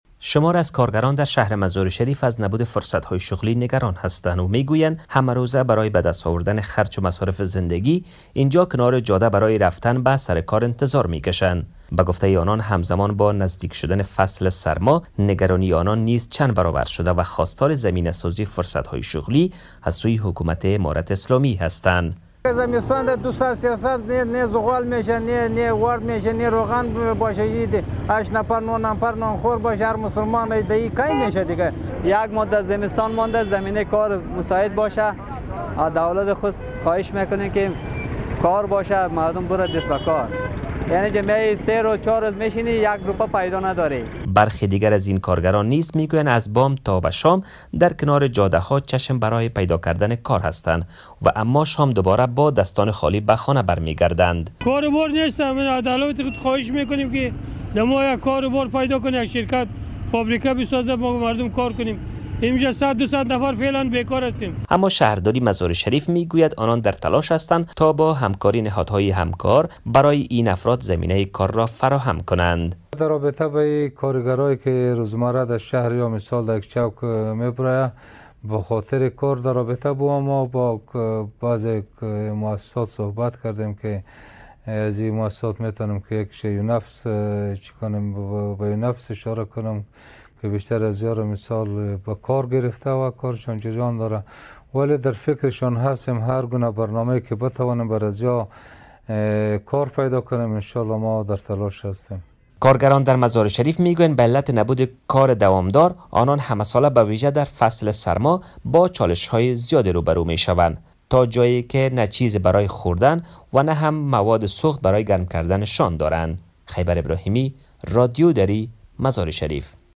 این کارگران می گویند: همه روزه برای تامین معاش زندگی، از بام تا شام در کنار جاده‌ها چشم به راه پیدا کردن کار می مانند و شام با دستان خالی به خانه برمی‌گردند.